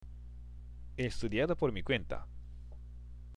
（エ　エストゥディアード　ポル　ミ　クエンタ）
の he は、次の estudiado と繋がって聞こえますので
無いように聞こえますが